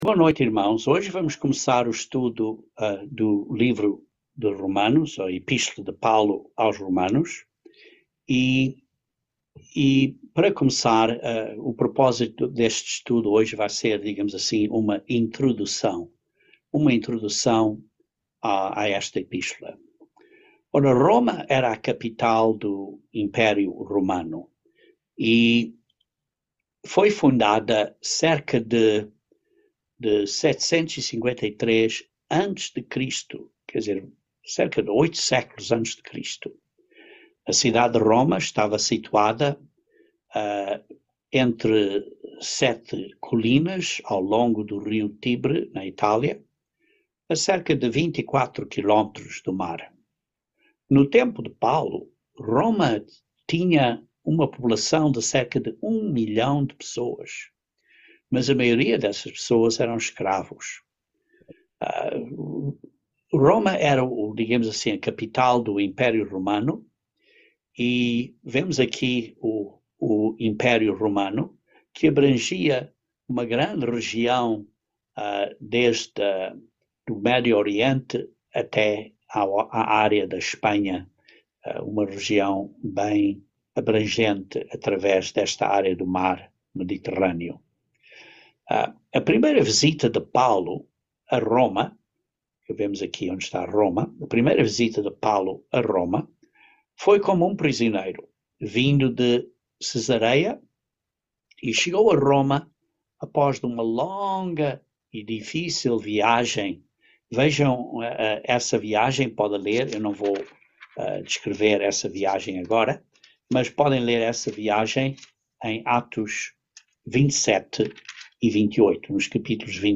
Given in Patos de Minas, MG